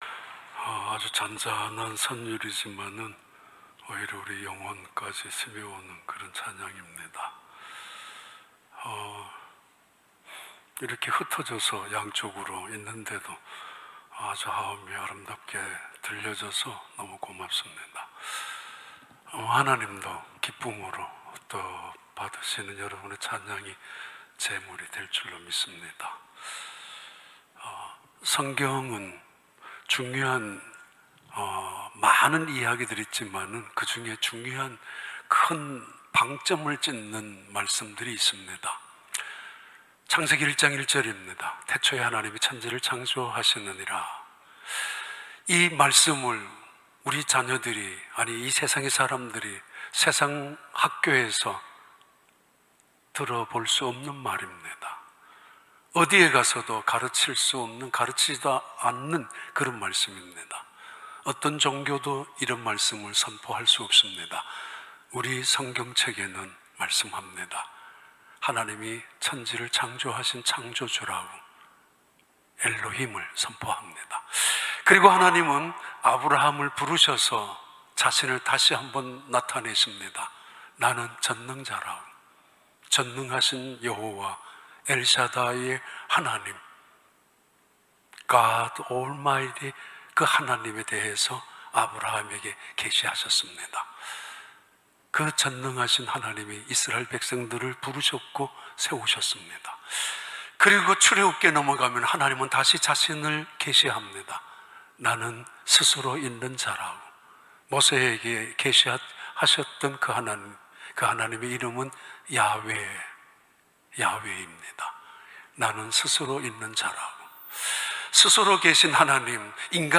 2021년 8월 22일 주일 3부 예배